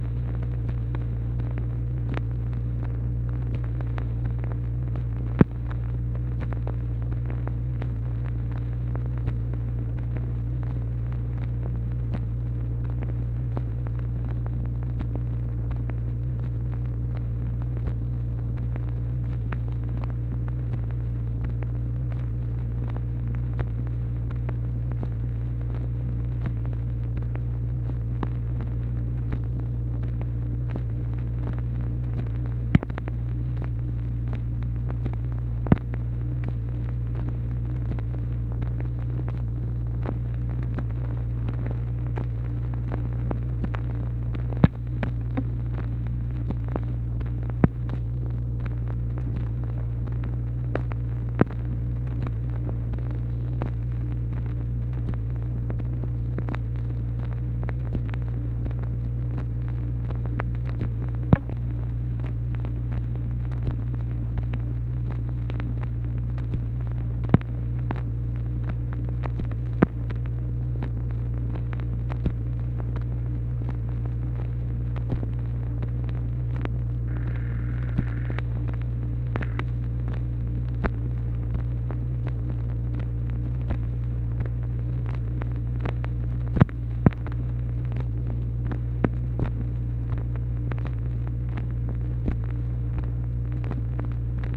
MACHINE NOISE, January 2, 1964